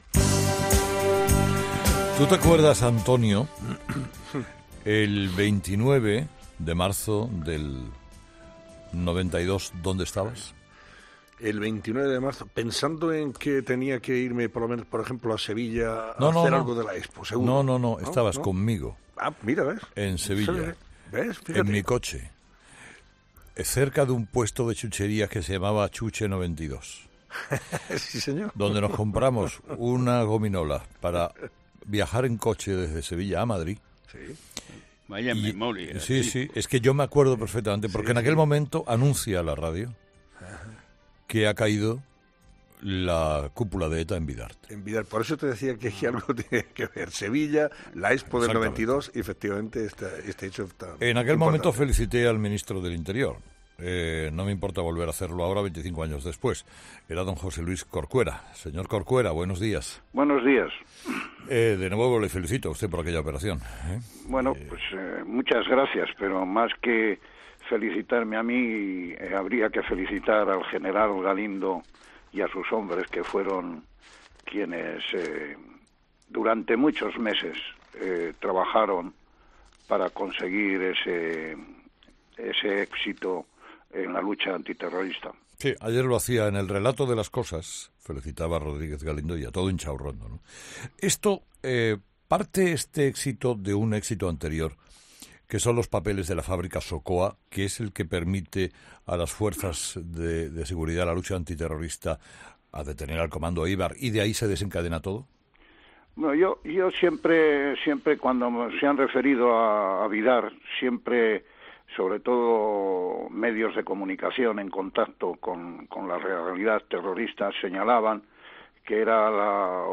Escucha le entrevista a José Luis Corcuera en 'Herrera en COPE'.